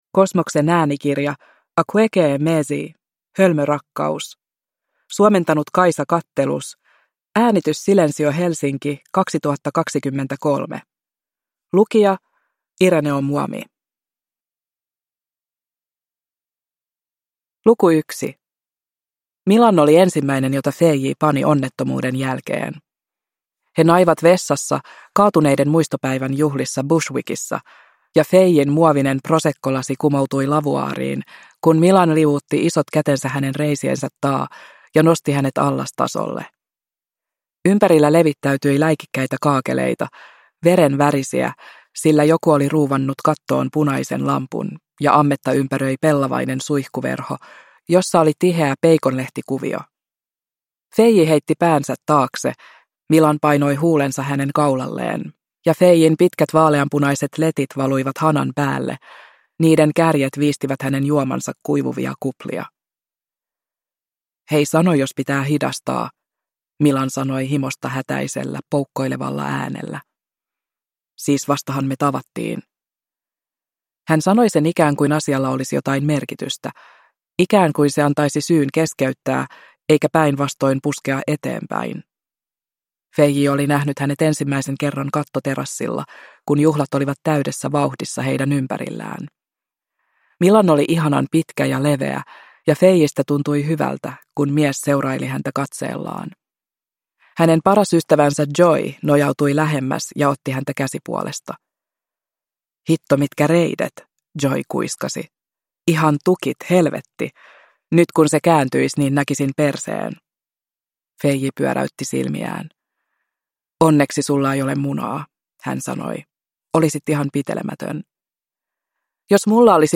Hölmö rakkaus – Ljudbok – Laddas ner